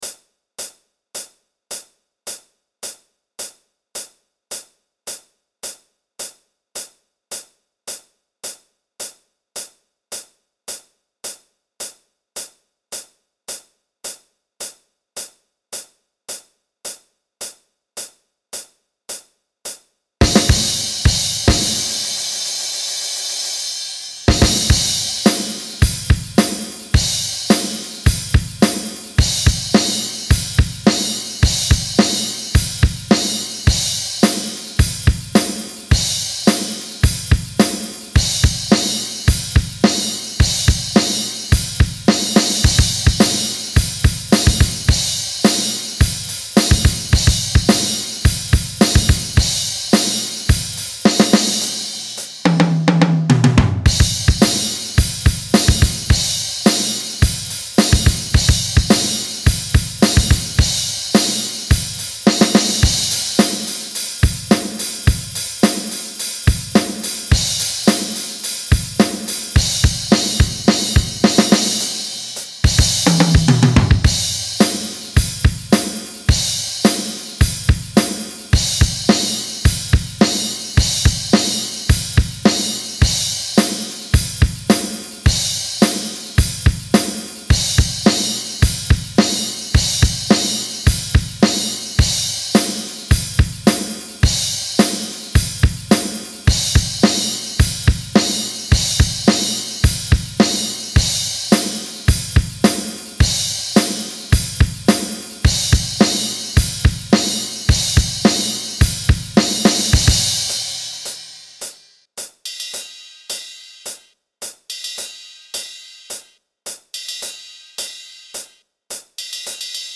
Drums only - create what you want